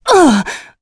Shamilla-Vox_Damage_03.wav